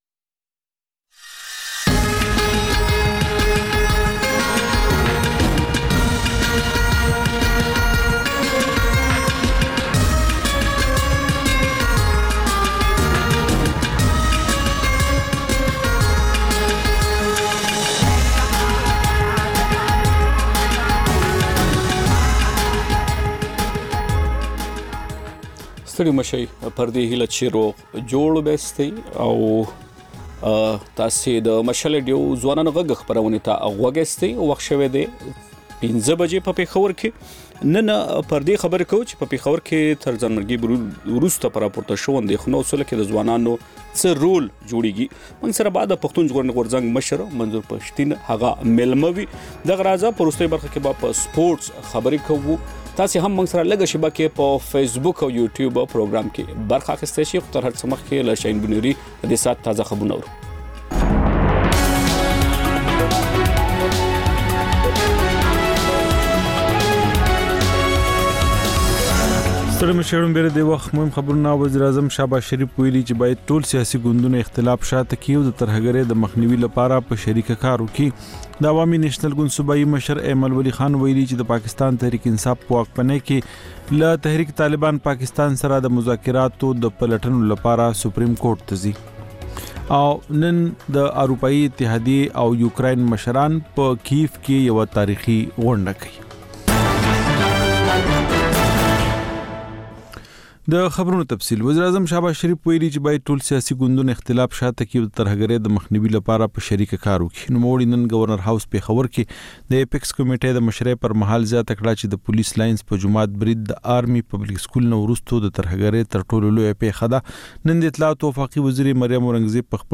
د مشال راډیو ماښامنۍ خپرونه. د خپرونې پیل له خبرونو کېږي، بیا ورپسې رپورټونه خپرېږي.
ځېنې ورځې دا مازیګرنۍ خپرونه مو یوې ژوندۍ اوونیزې خپرونې ته ځانګړې کړې وي چې تر خبرونو سمدستي وروسته خپرېږي.